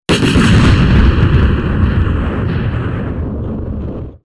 EXPLOSION
Descargar EFECTO DE SONIDO DE AMBIENTE EXPLOSION - Tono móvil
explosion.mp3